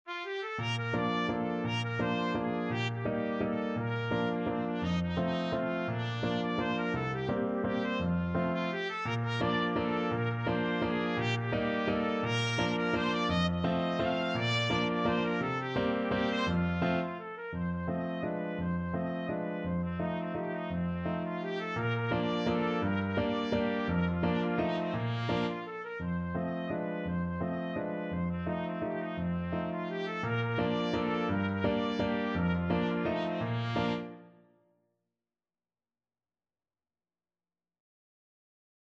Free Sheet music for Trumpet
Trumpet
Bb major (Sounding Pitch) C major (Trumpet in Bb) (View more Bb major Music for Trumpet )
3/4 (View more 3/4 Music)
Classical (View more Classical Trumpet Music)